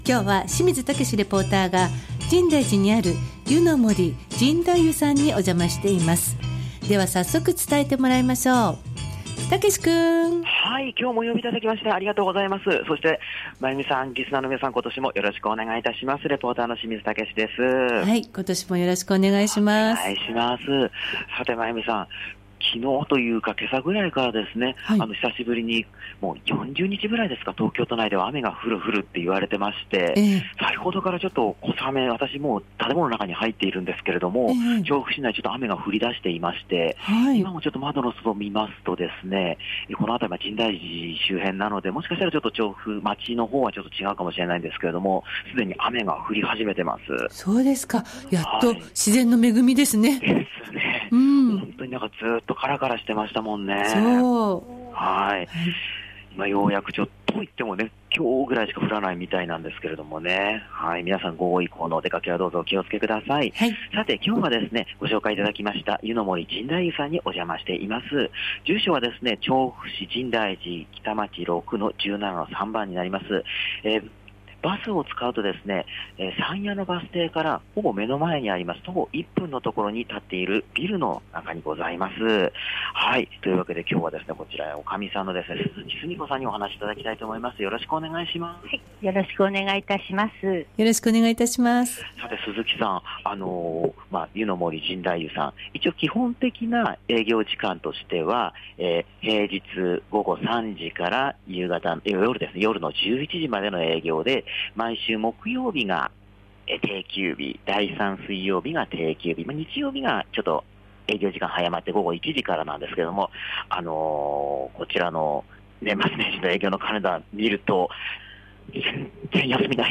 小雨が降る中からお届けした本日の街角レポートは年末年始休まず営業をされた「湯の森 深大湯」さんからのレポートです！